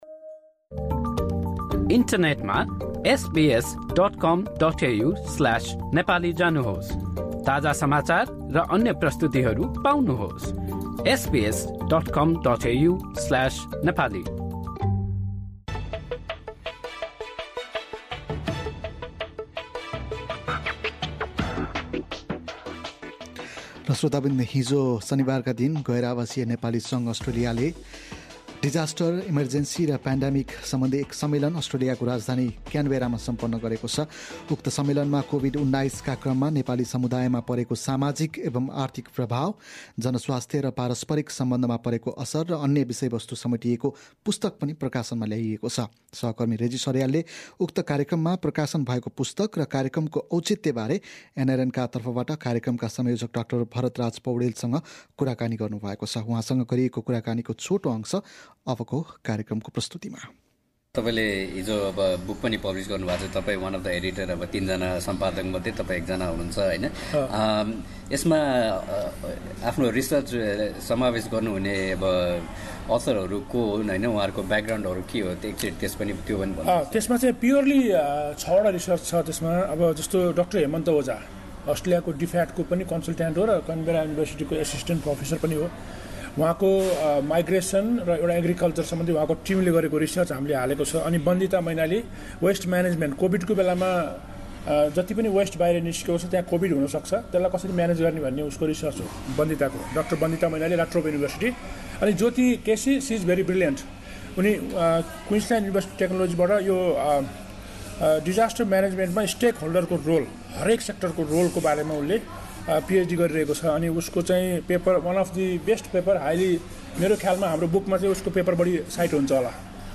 कुराकानी